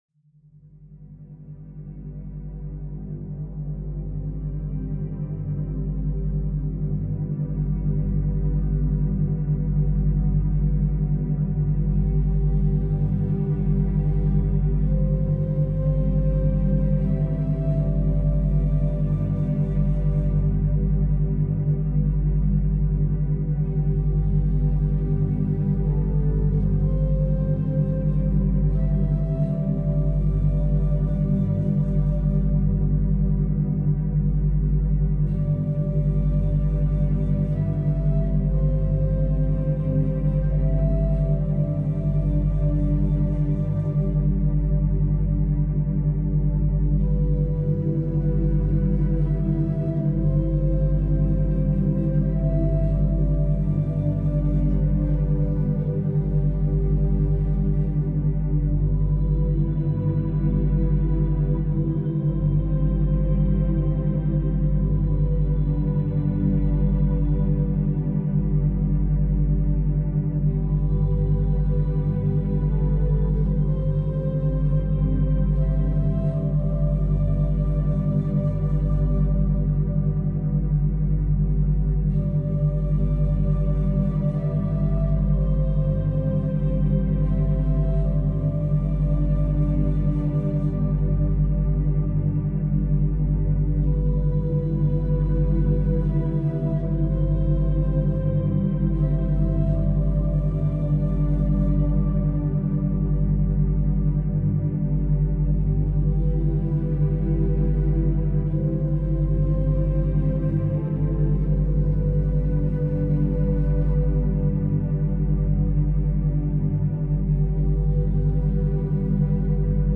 Pluie nocturne naturelle · méthode scientifique pour étude paisible